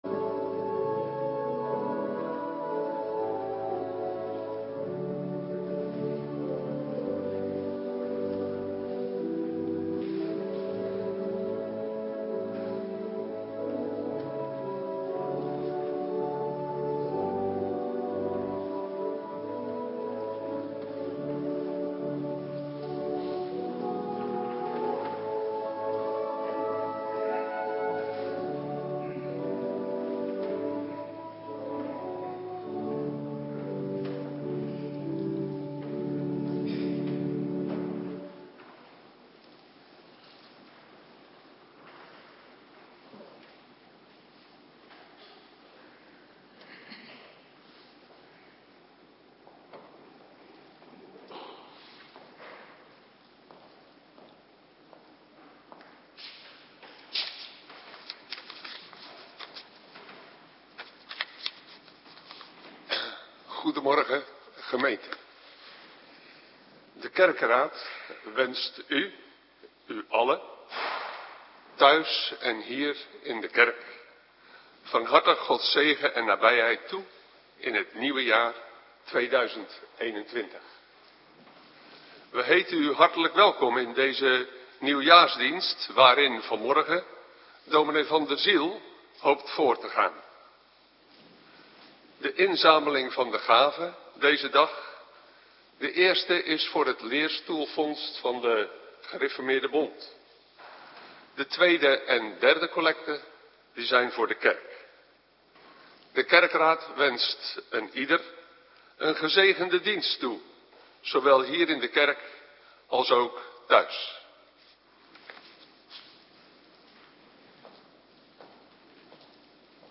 Nieuwjaarsdienst - Cluster 2